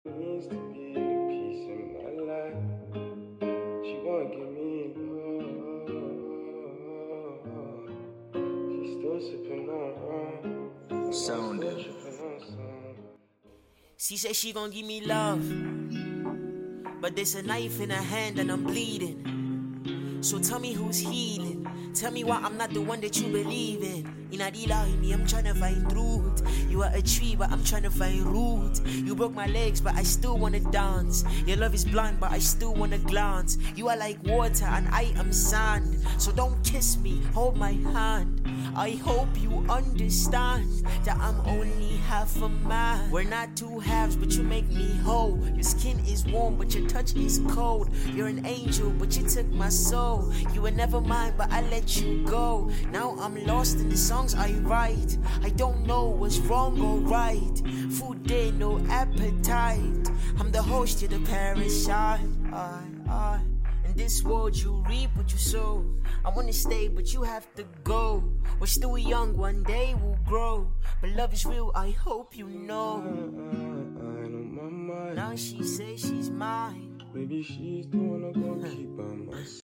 had to sing along